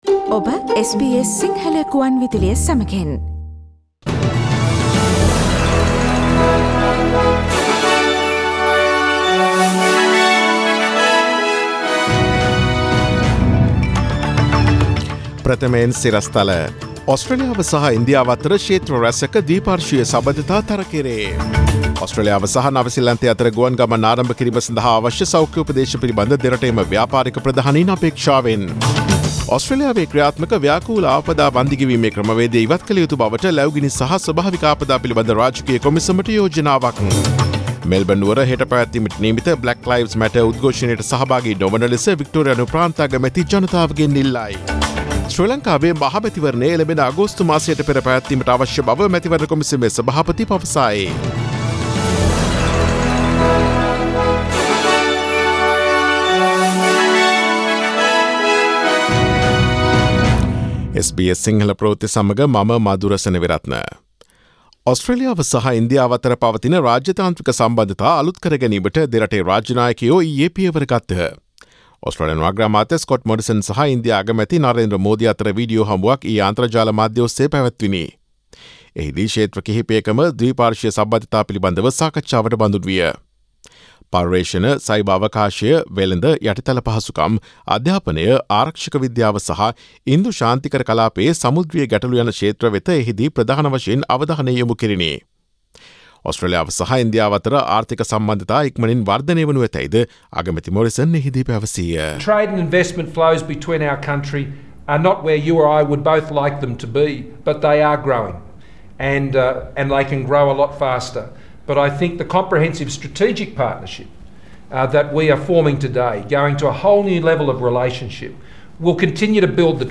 Daily News bulletin of SBS Sinhala Service: Friday 05 June 2020
Today’s news bulletin of SBS Sinhala Radio – Friday 05 June 2020 Listen to SBS Sinhala Radio on Monday, Tuesday, Thursday and Friday between 11 am to 12 noon